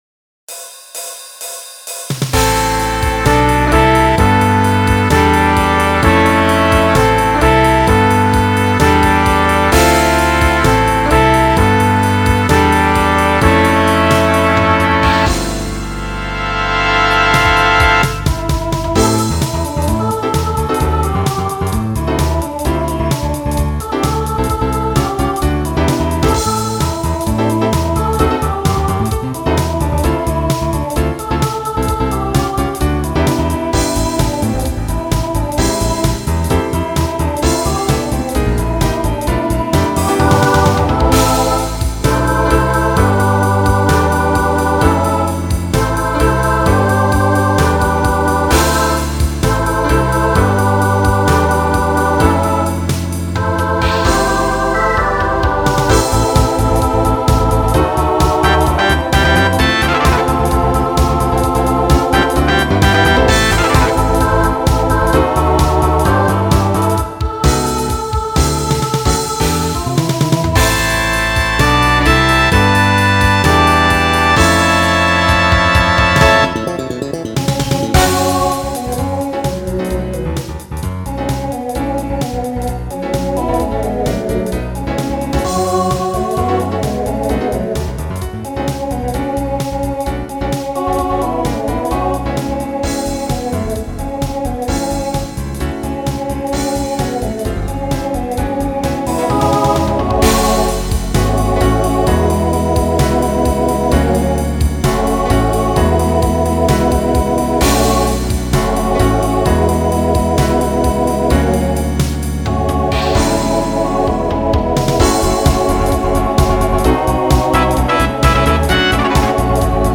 Voicing Mixed Instrumental combo Genre Pop/Dance